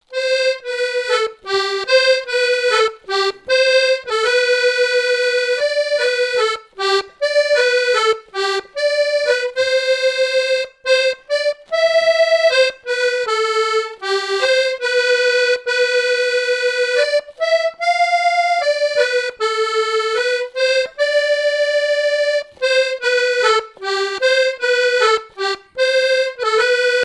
danse : valse
Enquête Arexcpo en Vendée-Association Héritage-C.C. Herbiers
Répertoire à l'accordéon diatonique
Pièce musicale inédite